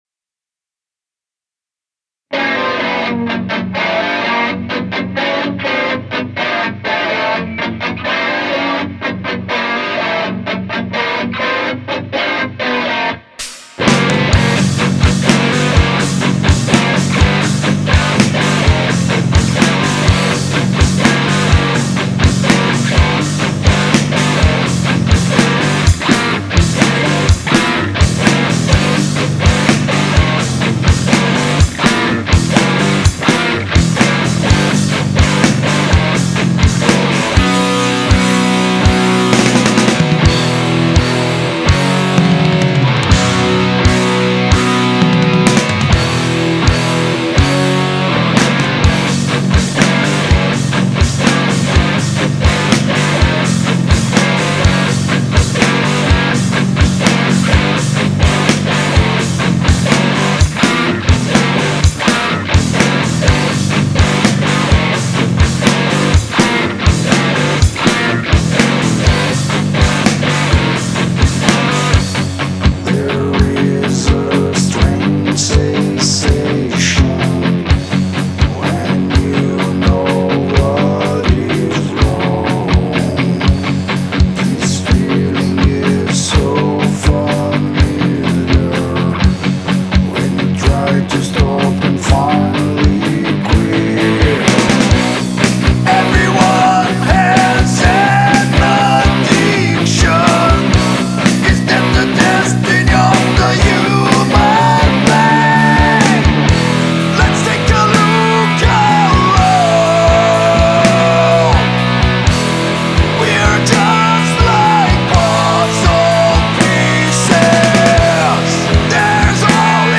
guitarra solo